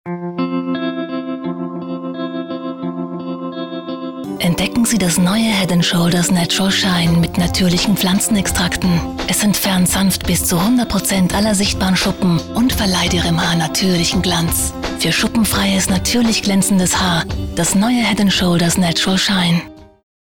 deutsche Sprecherin.
Sprechprobe: Industrie (Muttersprache):
german female voice over artist, dubbing